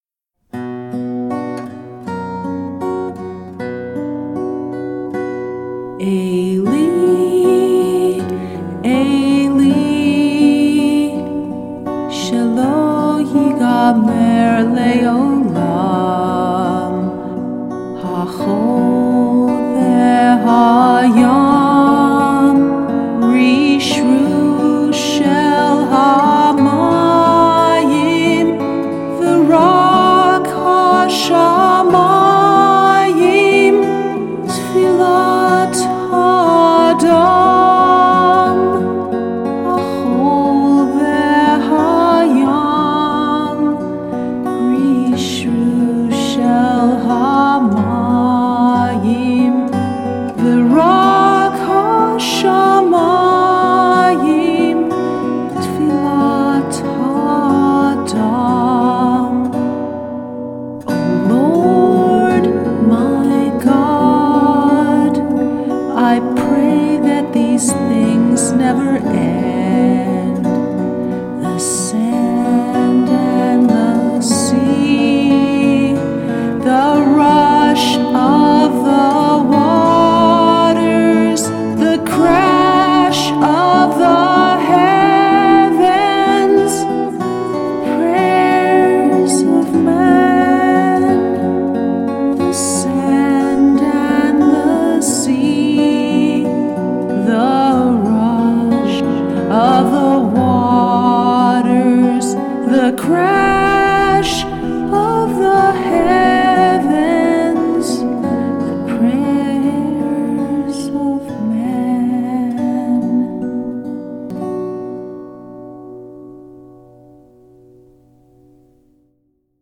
ELI ELI – HOME RECORDING OF A SONG BY HANNA SENESH – 1/9/11